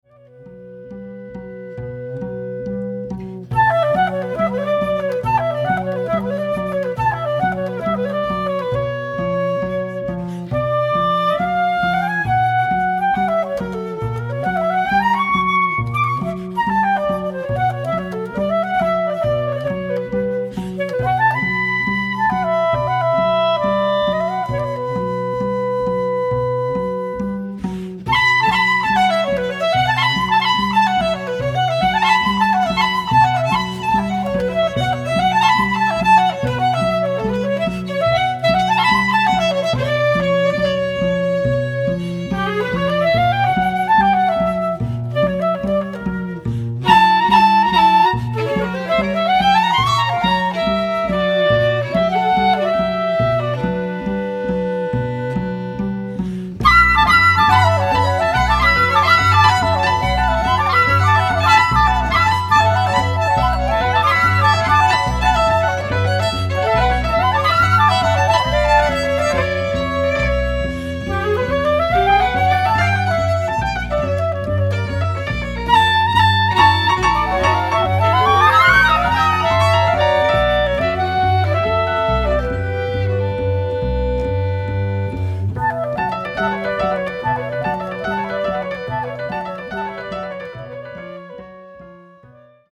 Nahráno v zimě 2003/2004 ve studiu PIVOX